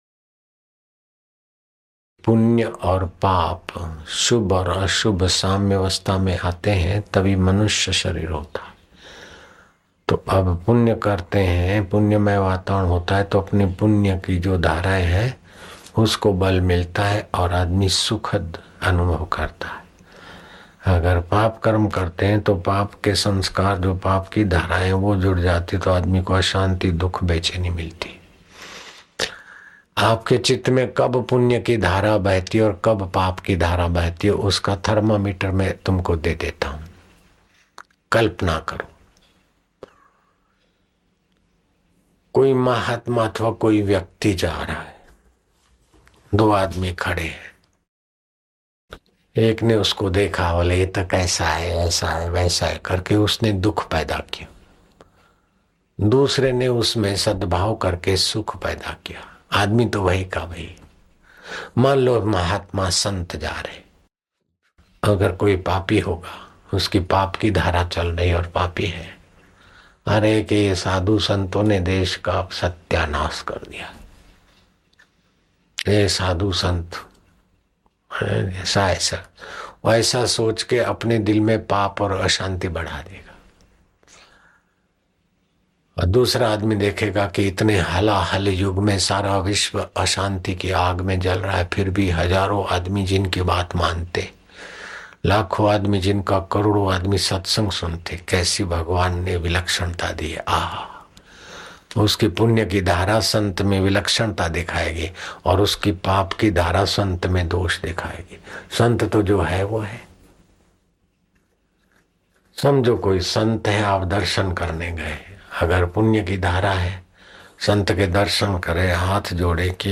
Audio Satsang MP3s of Param Pujya Sant Shri Asharamji Bapu Ashram